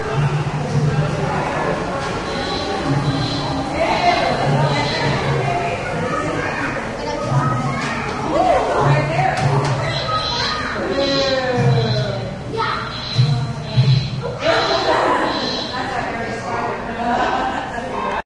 灵长类动物 " 松鼠猴02
描述：松鼠猴在室内展览中叫唤和走动。蜘蛛猴在背景中尖叫。用Zoom H2.
标签： 场记录 灵长类 热带雨林 松鼠猴 动物园
声道立体声